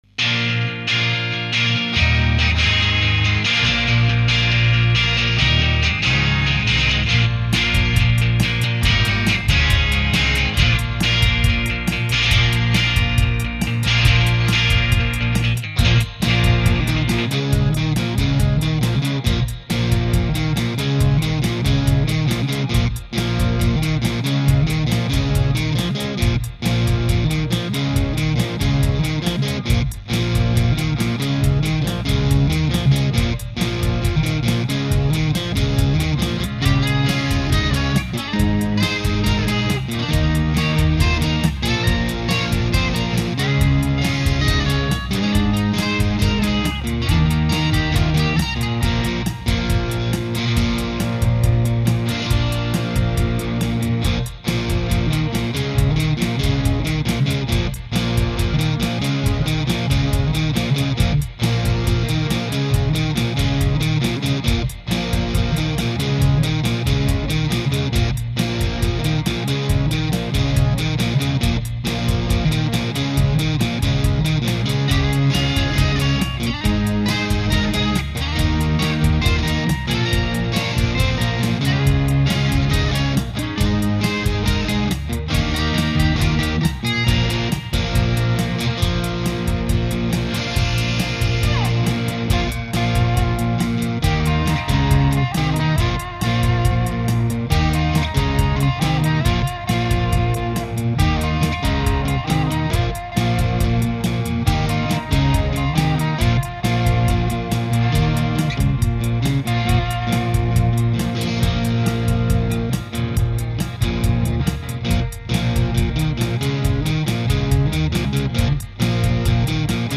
Two guitars and a bass.